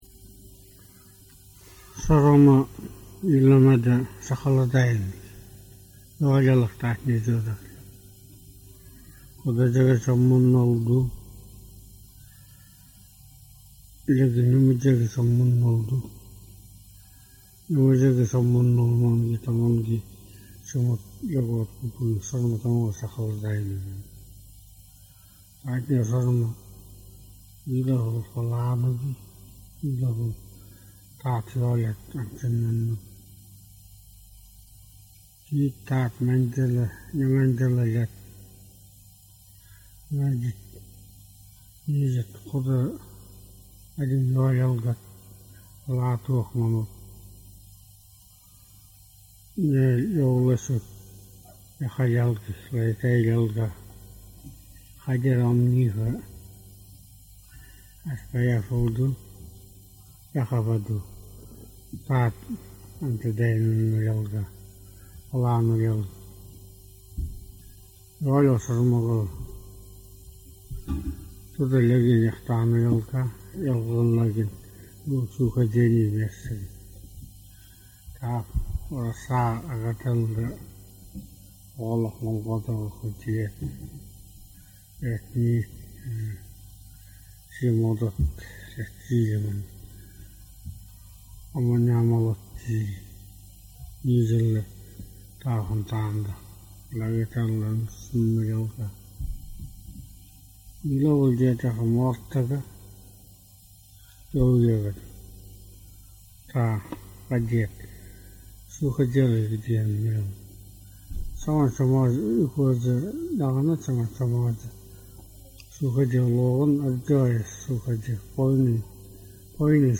The story describes the traditional method of treating jaundice (icterus) referred to in Yukaghir as SaqalEdajl 'yellowing'.